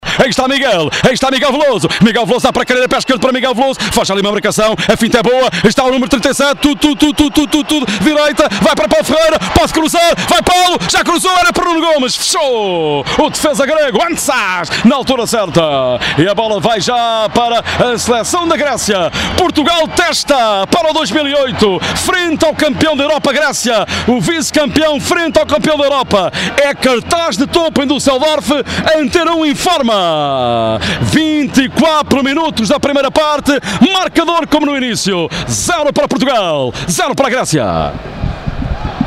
Portugal x Grécia: narração